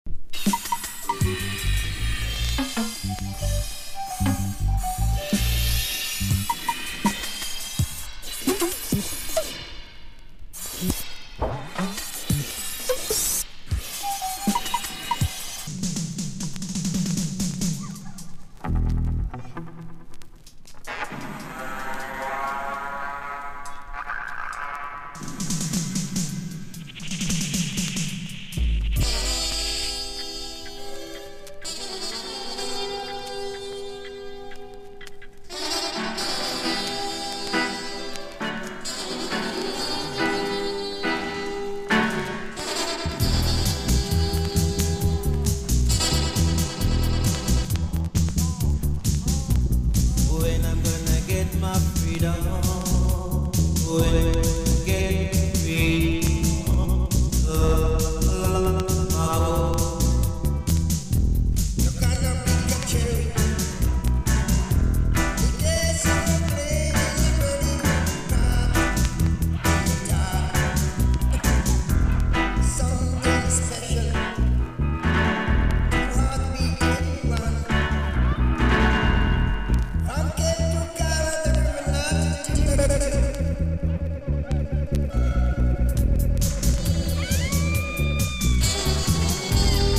• REGGAE-SKA
DUB / UK DUB / NEW ROOTS